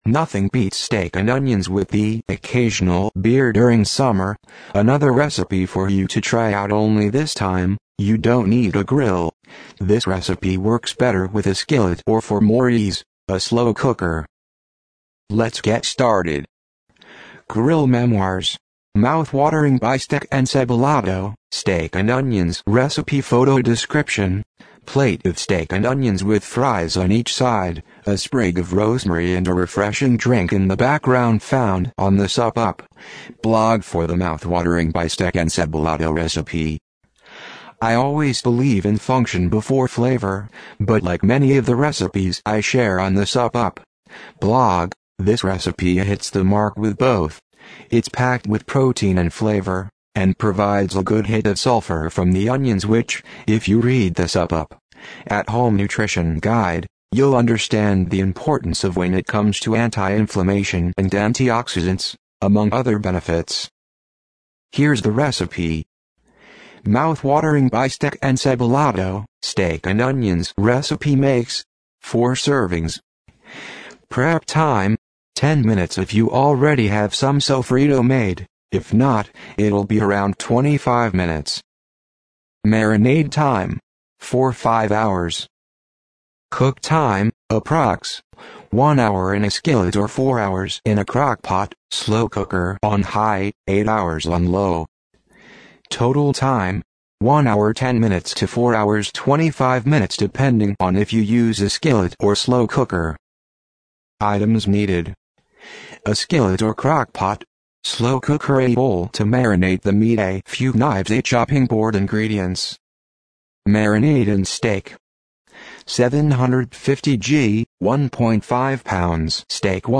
SUPP UP. TTS – Click below to listen to the text-to-speech audio version (for the hearing impaired) of this post now:
Grill-Memoirs-Mouth-Watering-Bistec-Encebollado-Recipe-SUPP-UP-TTS.mp3